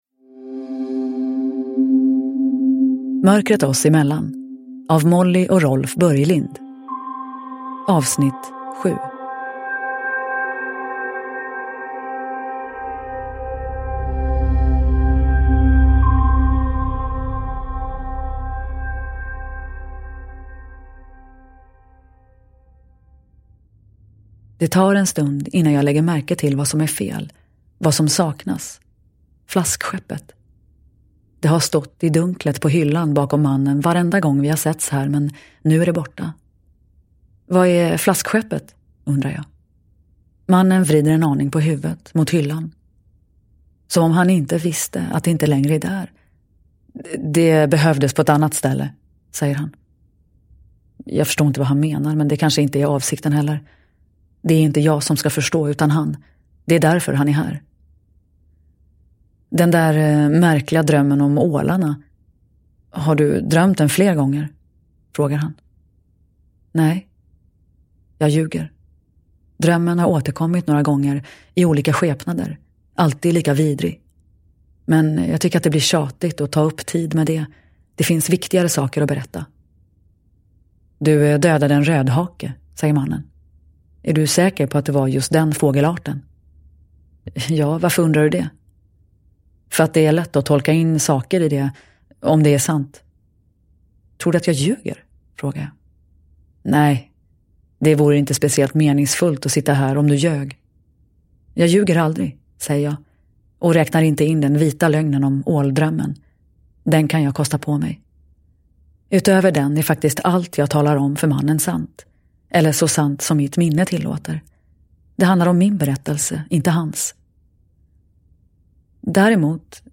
Mörkret oss emellan. 7 – Ljudbok